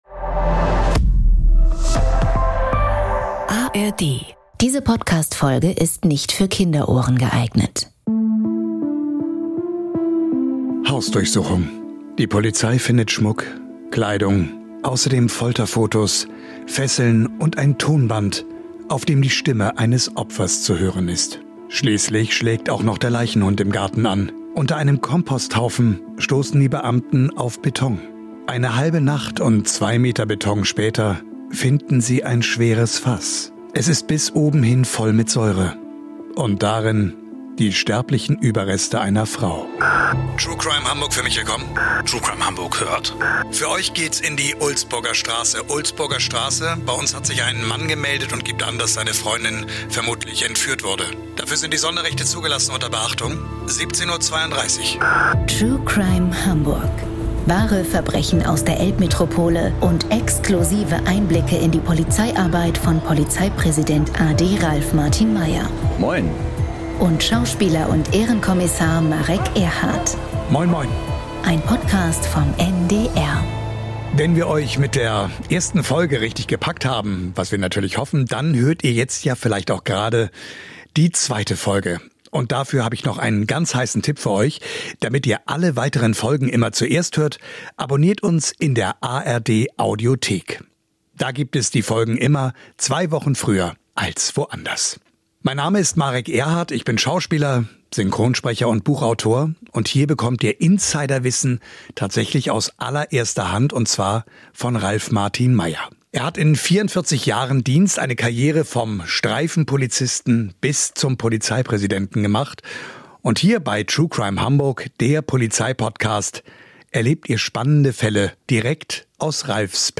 Im Gespräch mit Schauspieler und Ehrenkommissar Marek Erhardt gibt Hamburgs Polizeipräsident a.D. Ralf Martin Meyer Einblicke in die damaligen Ermittlungen und erzählt von einem patriarchalen System, dass die Aufklärung dieser Gräueltaten fast verhindert hätte.